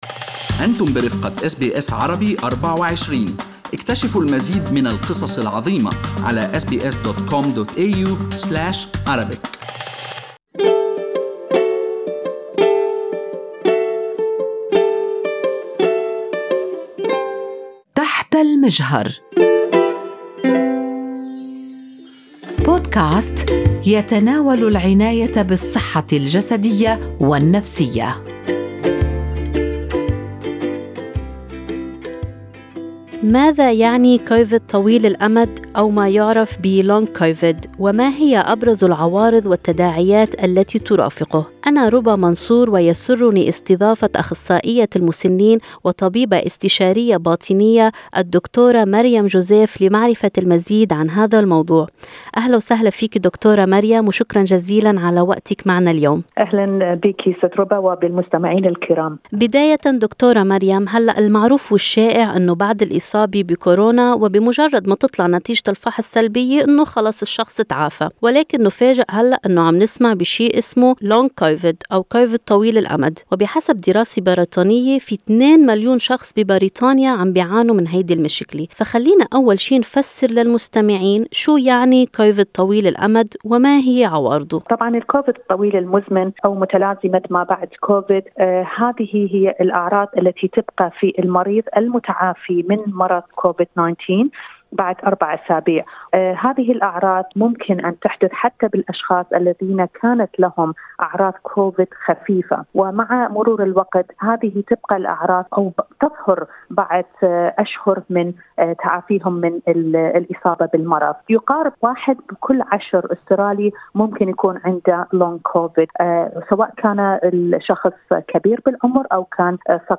في حديث أجرته SBS عربي 24